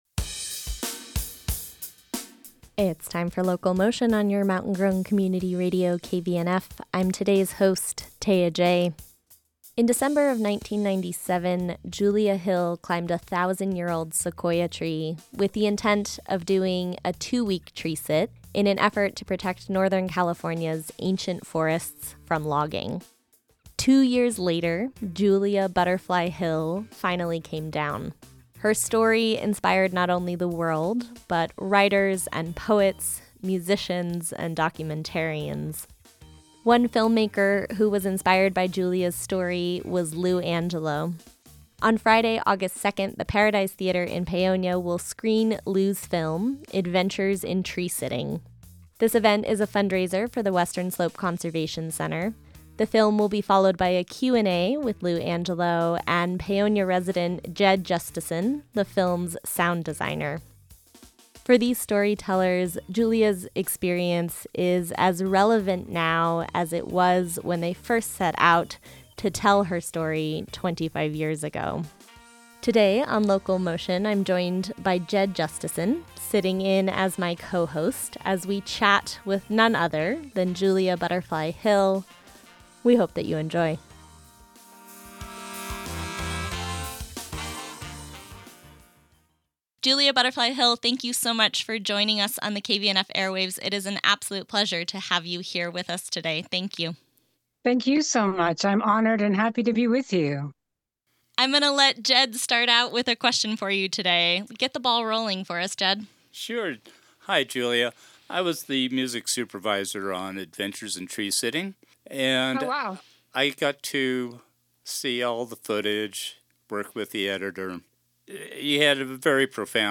In-depth local public affairs reporting and interviews from around the KVNF listening area.